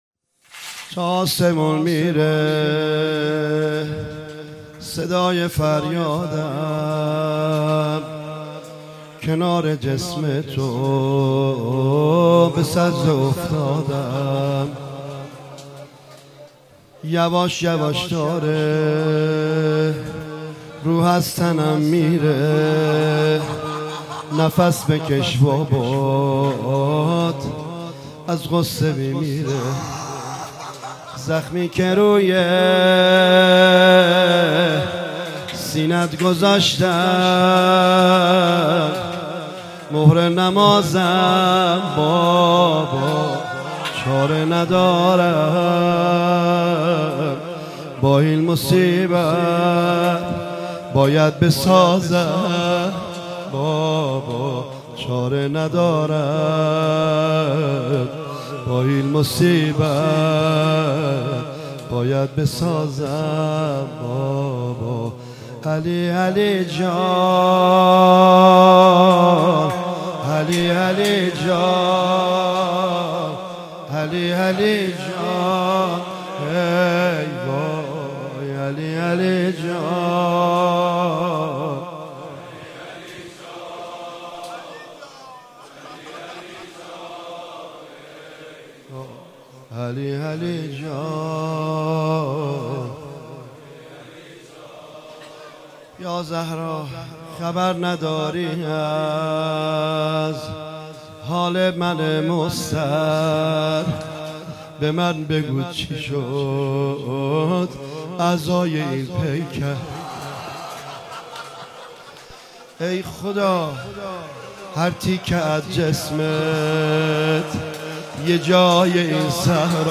مداحی
در شب هشتم محرم الحرام 1396.